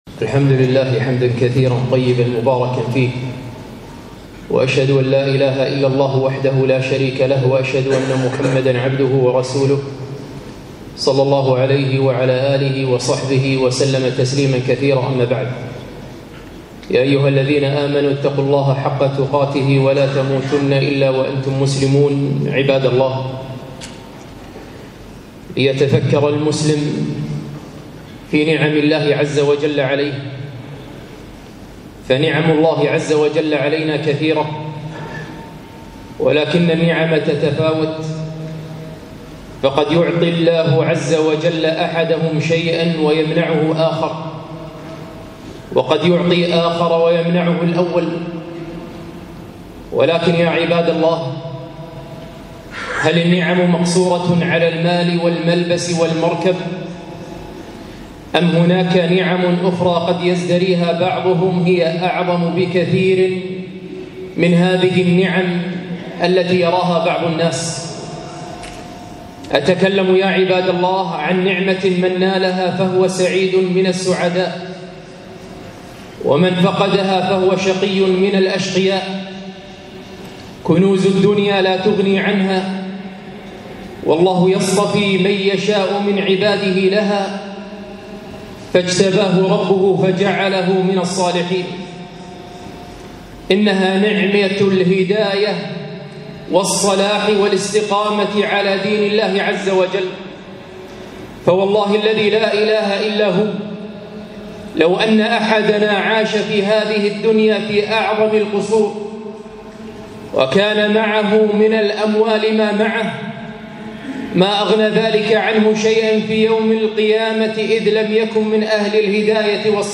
خطبة - هل تعرف هذه النعمة؟